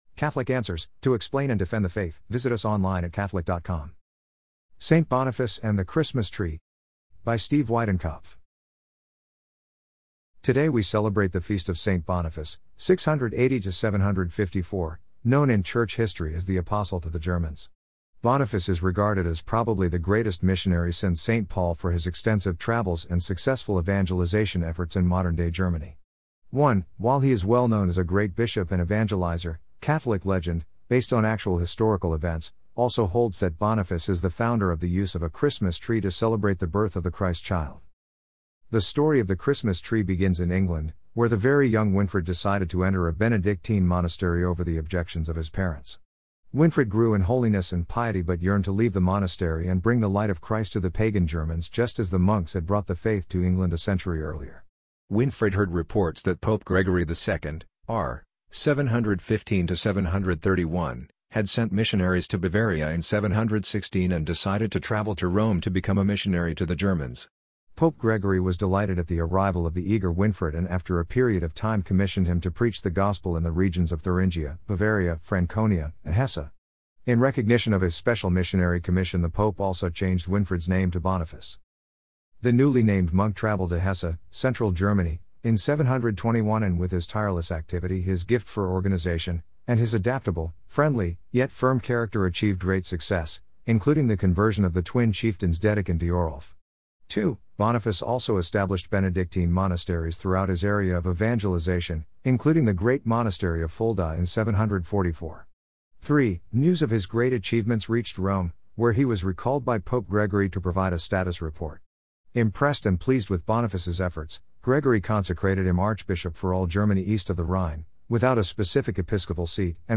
amazon_polly_13184.mp3